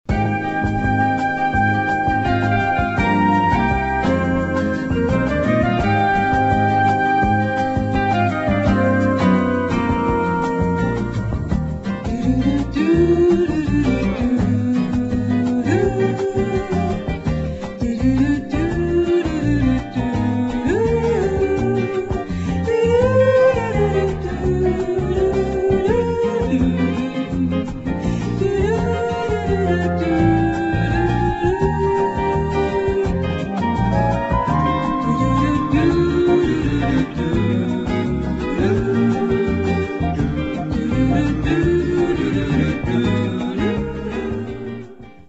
[ EASY LISTENING / SOUNDTRACK ]
男女混成スキャットをフーチャーした、ブラジリアン・フレーバーで人気のレア盤で知られる１枚！！